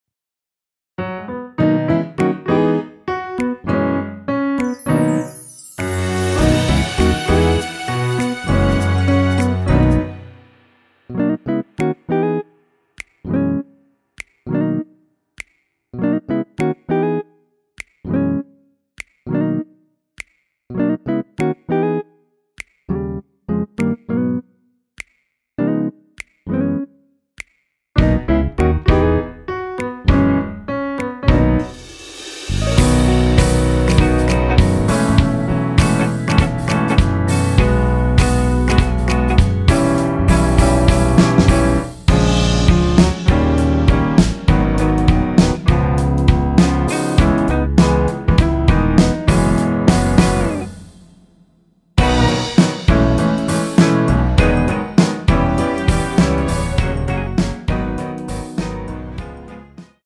원키에서 (-4)내린 MR 입니다.
앞부분30초, 뒷부분30초씩 편집해서 올려 드리고 있습니다.
중간에 음이 끈어지고 다시 나오는 이유는